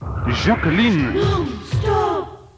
Here are some jingles.